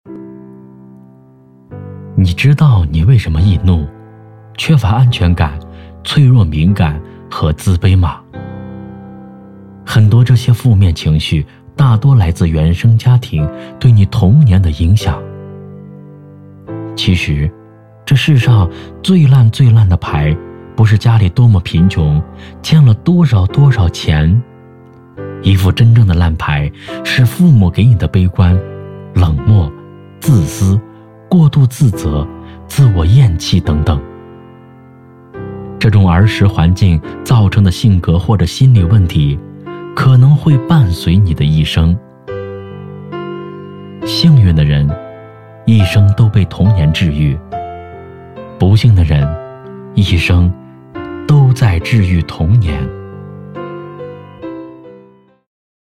【男80号专题】情感
【男80号专题】情感.mp3